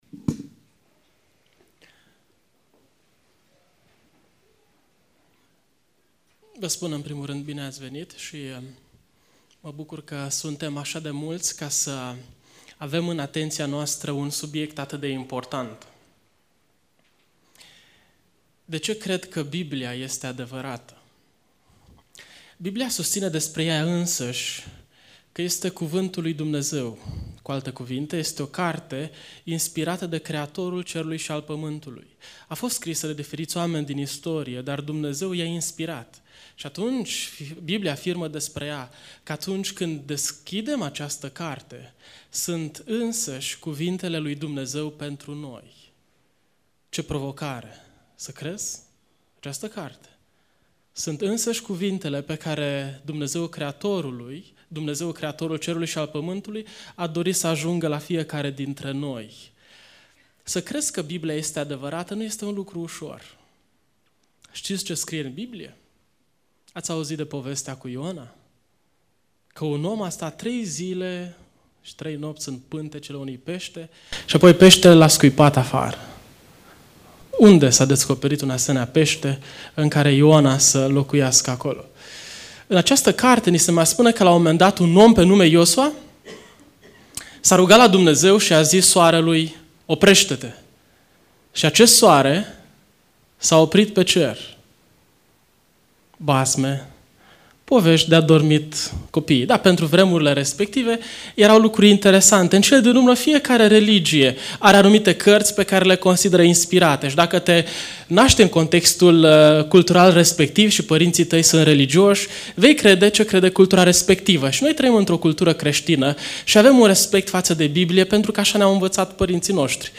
Conferinta Este Biblia adevarata?